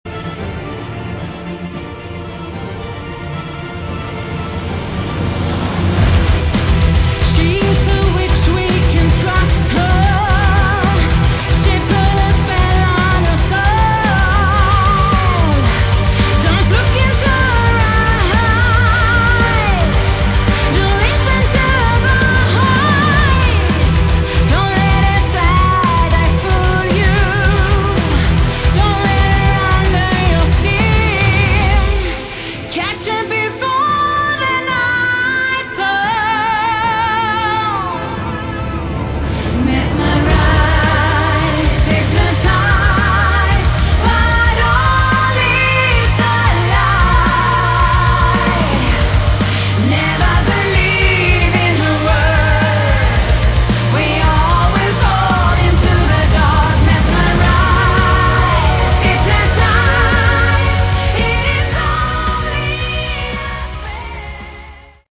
Category: Symphonic Concept Album